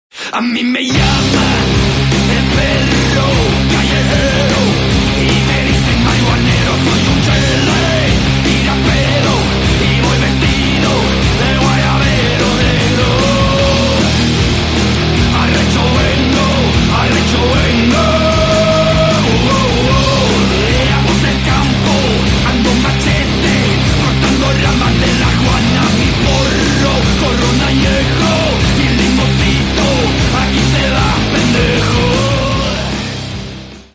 Громкие Рингтоны С Басами
Рок Металл Рингтоны